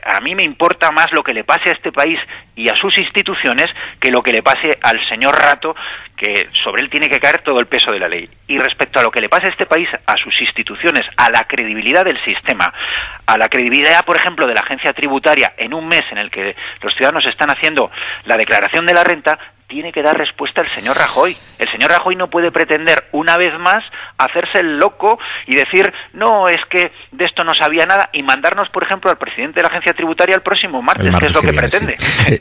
Fragmento de la entrevista a Antonio Hernando en la Cadena SER el 17/04/2015 en la que asegura que Rajoy ya no puede seguir haciéndose el loco después de lo sucedido con Rodrigo Rato y la amnistía fiscal